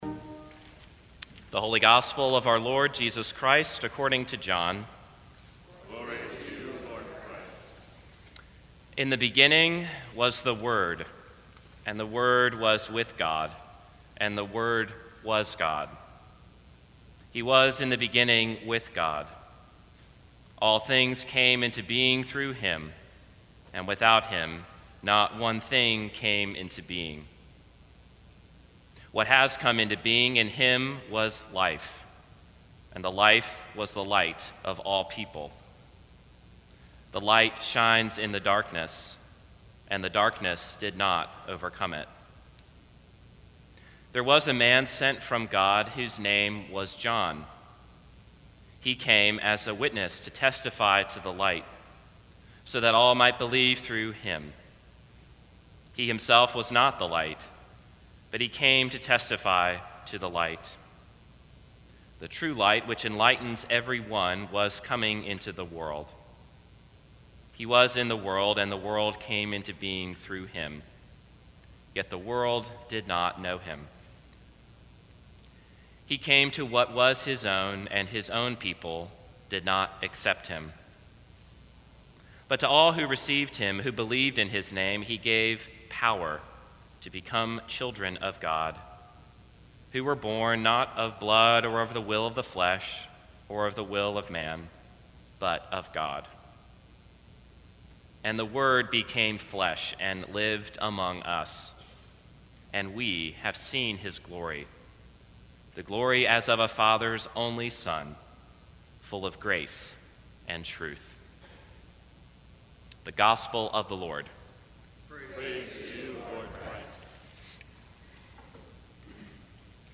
Sermons from St. Cross Episcopal Church
Christmas Day